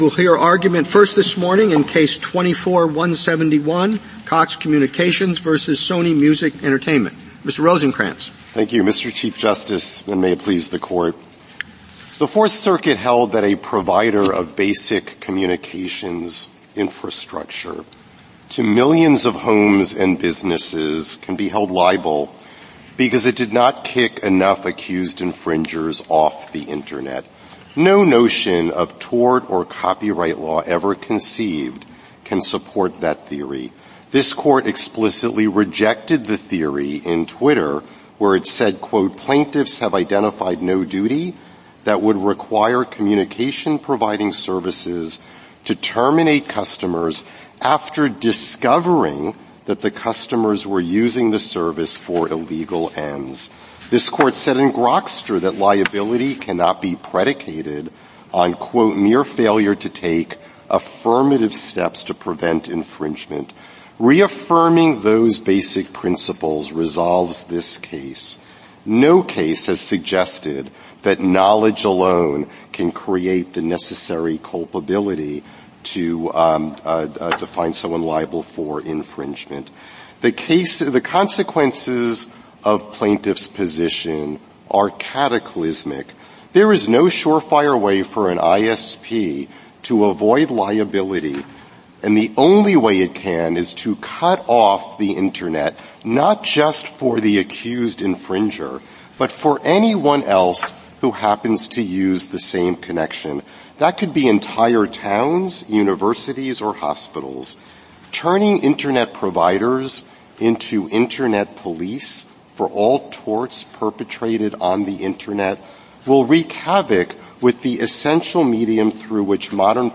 Supreme Court Oral Arguments